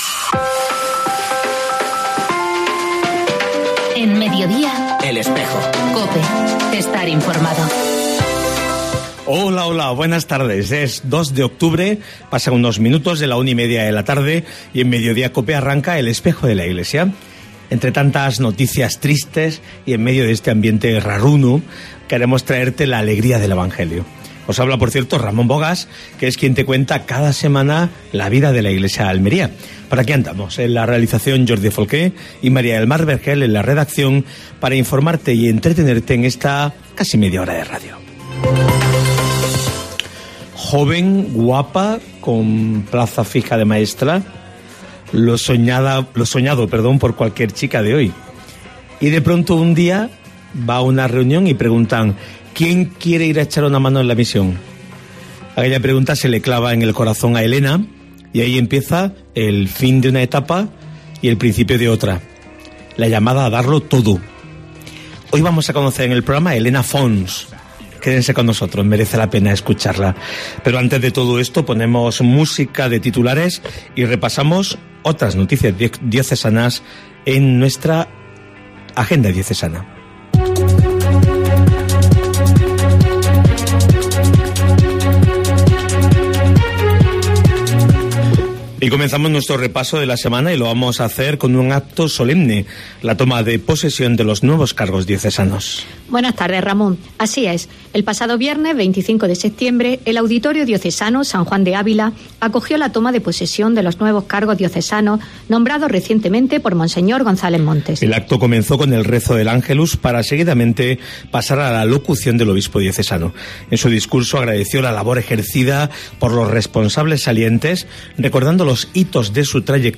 AUDIO: Actualidad de la Iglesia en Almería. Entrevista